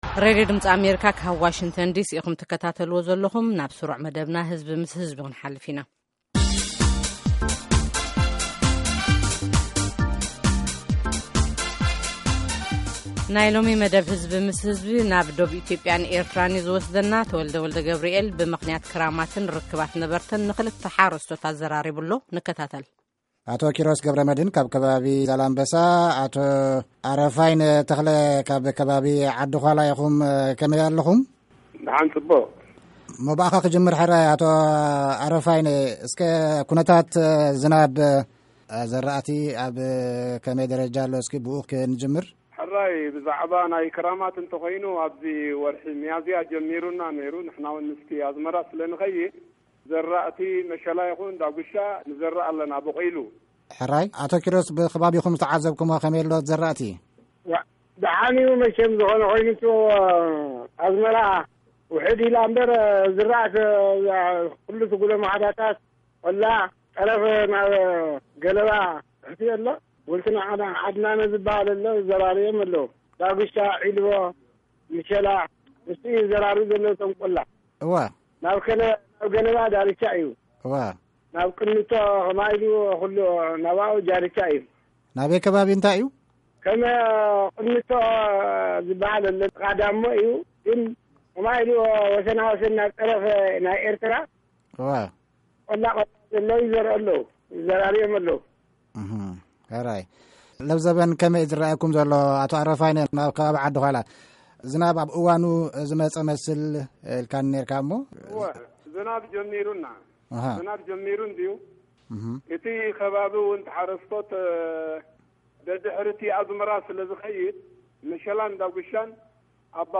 Eritrean and Ethiopian farmers discuss